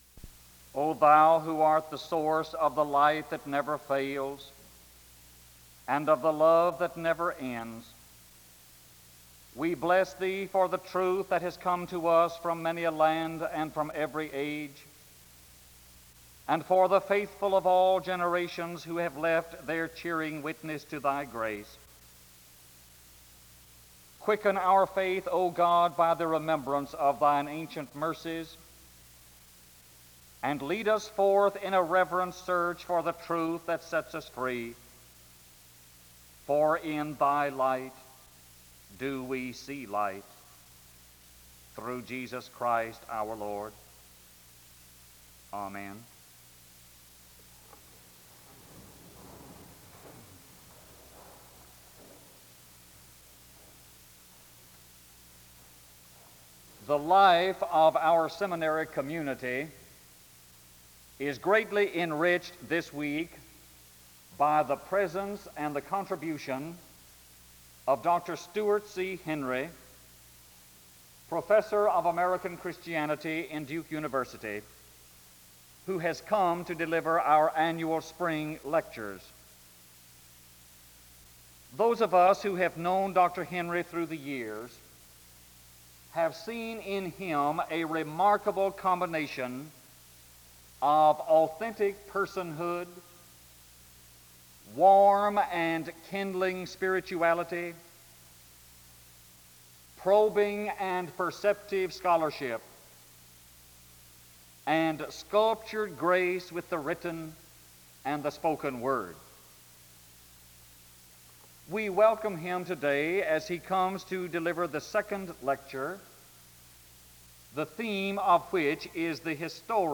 The service begins with a prayer (0:00-0:54).
The service continues with a period of singing (2:14-5:27).
SEBTS Chapel and Special Event Recordings